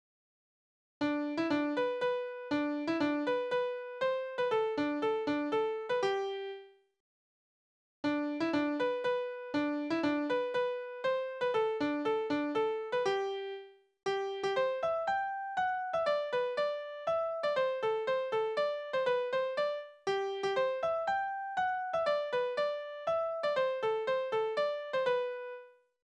Balladen: vergebliche Jagd
Tonart: G-Dur
Taktart: 3/4
Tonumfang: Oktave, Quarte